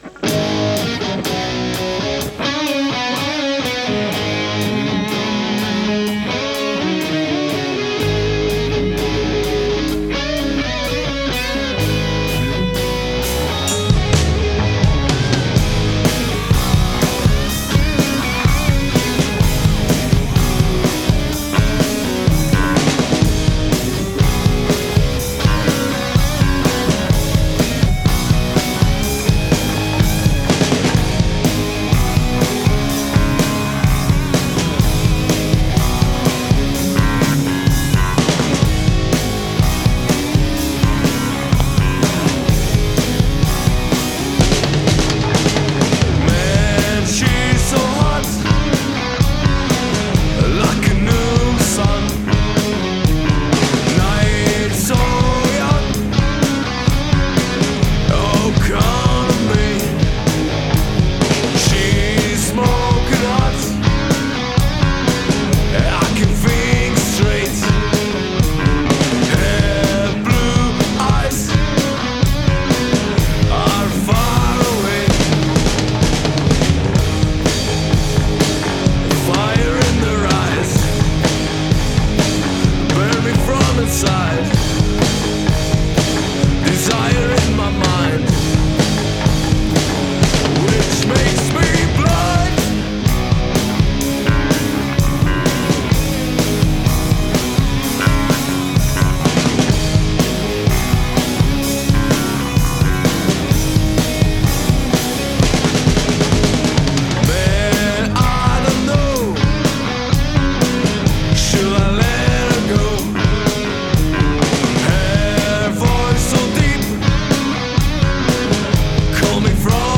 Gramy mocną muzykę gitarową.
Gatunek: Metal
wokal, gitara
perkusja